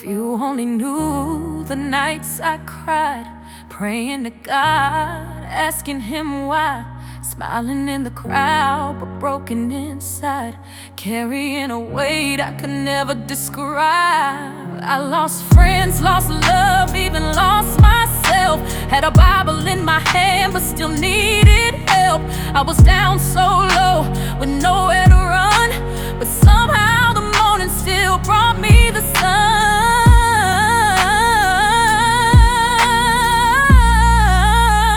Жанр: R&B / Электроника / Соул